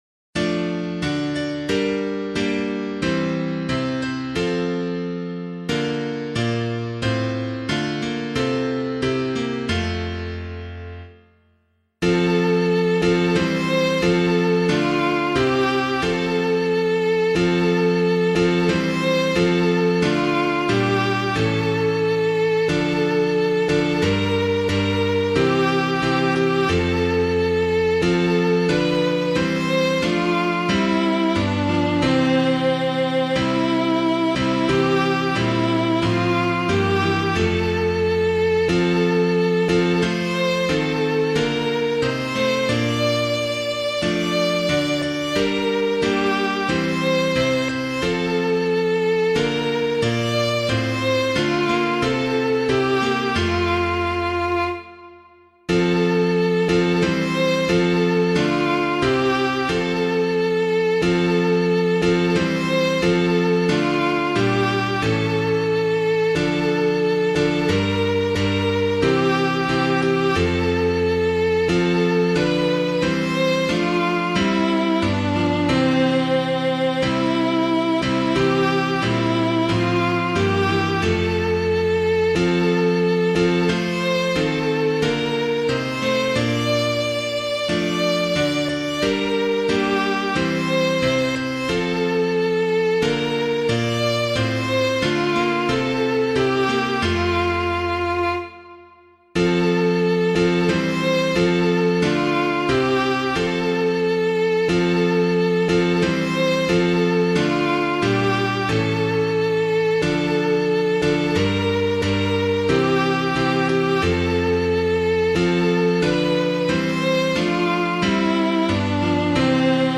piano
Hail Redeemer King Divine [Brennan - SAINT GEORGE'S WINDSOR] - piano.mp3